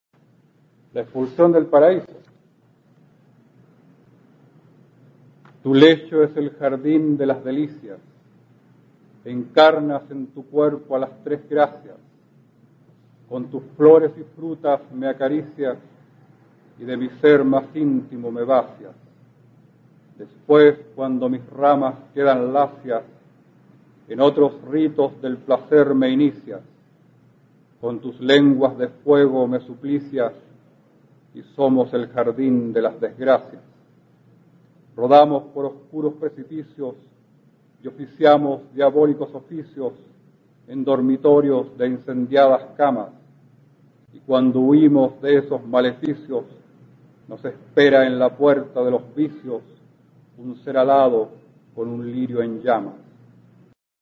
Escucharás aquí al poeta chileno Óscar Hahn, perteneciente a la Generación del 60, recitando su soneto La expulsión del paraíso, inspirado en un cuadro de Masaccio, pintor florentino del siglo XV.